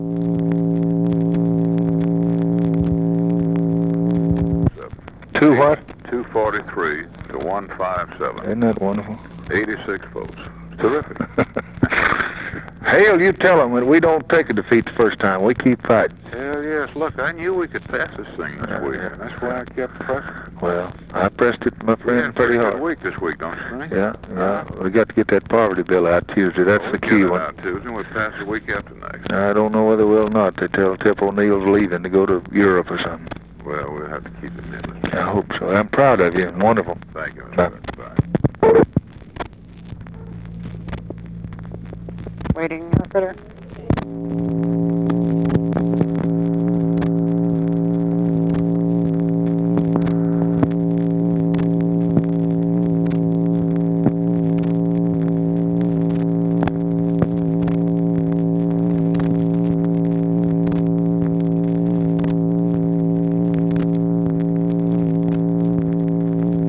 Conversation with HALE BOGGS, June 11, 1964
Secret White House Tapes